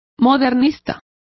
Complete with pronunciation of the translation of modernists.